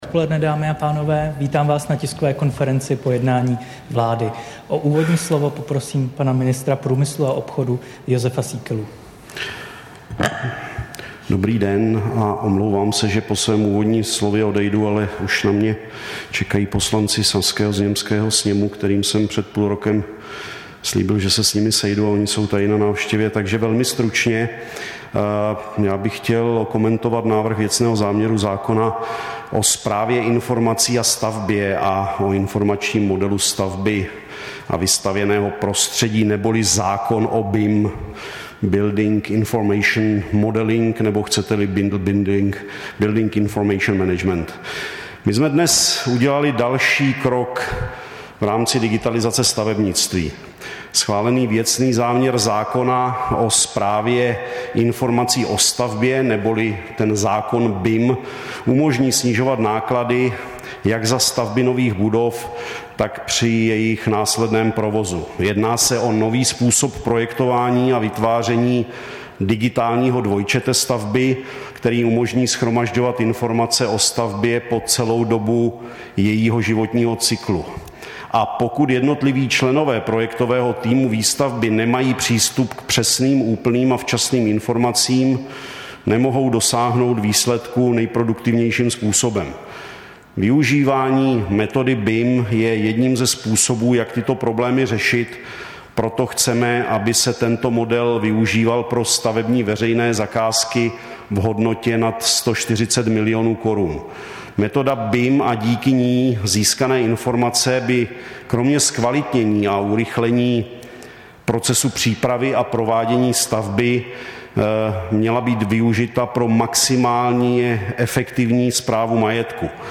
Tisková konference po jednání vlády, 3. května 2023